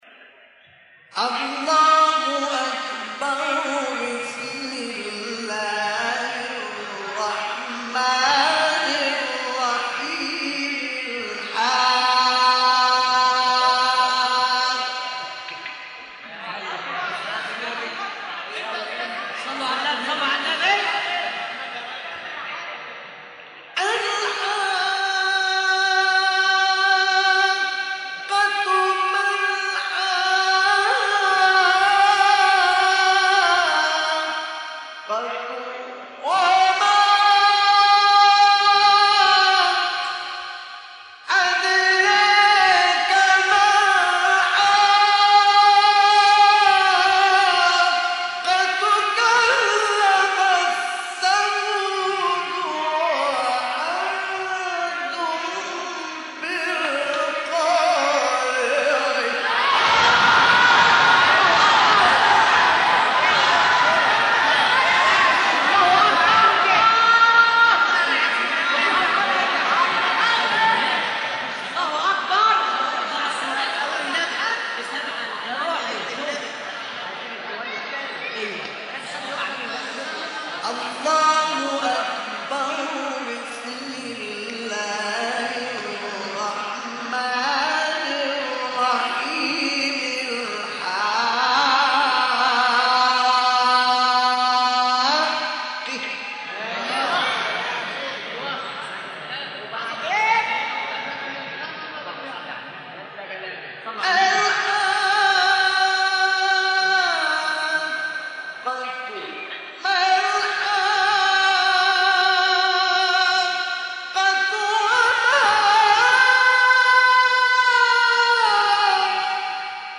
سوره: حاقه آیه: 1-24 استاد: مصطفی اسماعیل مقام:‌ مرکب خوانی(سه گاه * رست) قبلی بعدی